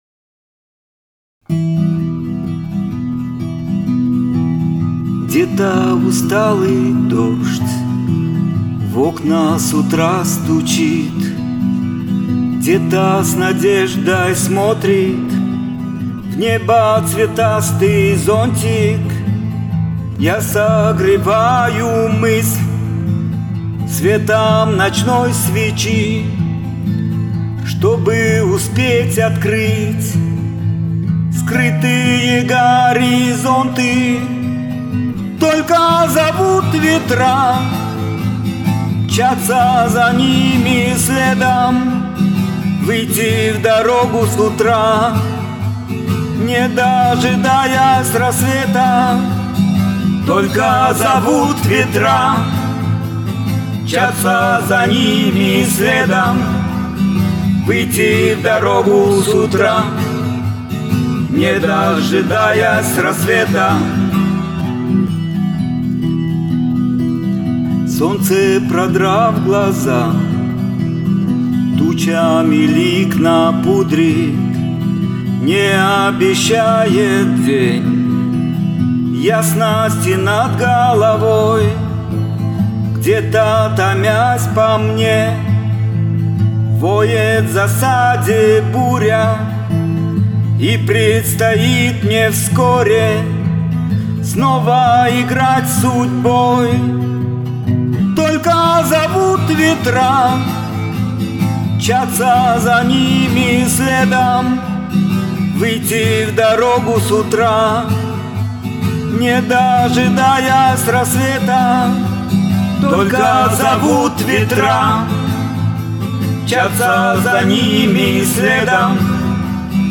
гитара
под аккомпанемент гитары